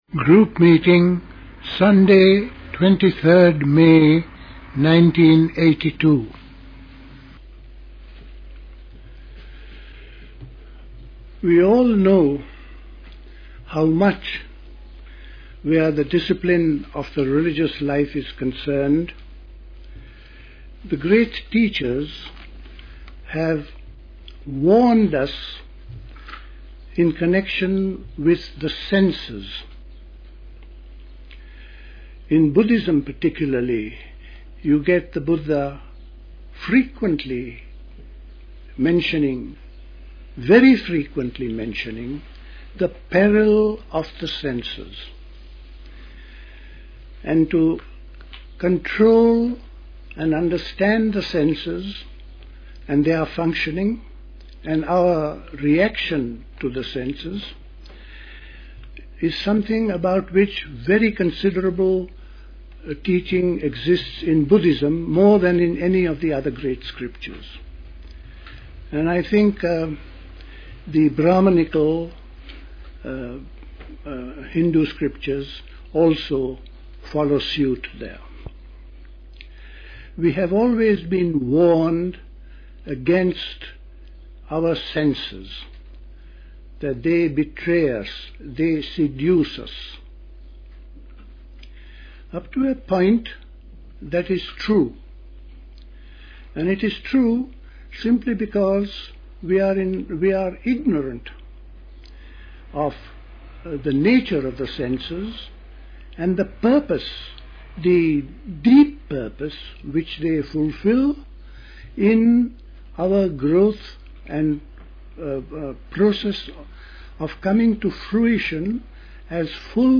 A talk
at Dilkusha, Forest Hill, London on 23rd May 1982